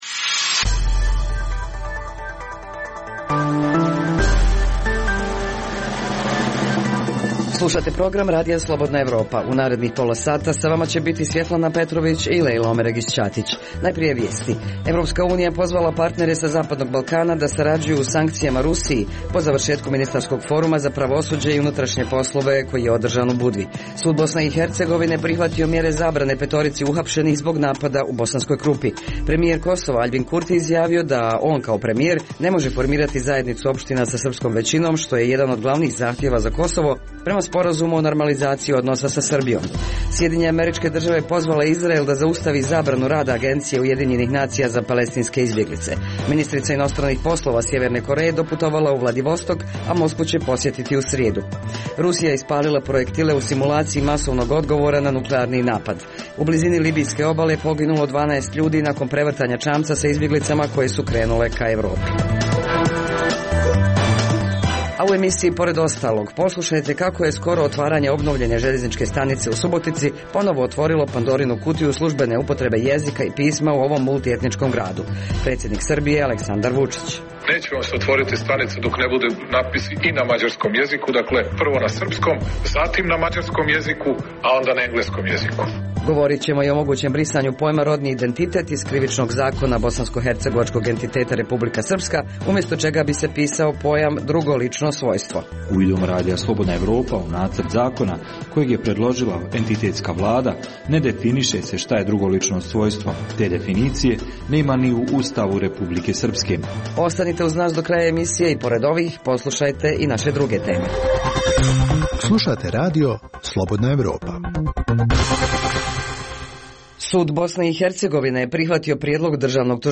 Preostalih pola sata emisije sadrži analitičke priloge iz svih zemalja regiona i iz svih oblasti, od politike i ekonomije, do kulture i sporta. Reportaže iz svakodnevnog života ljudi su svakodnevno takođe sastavni dio “Dokumenata dana”.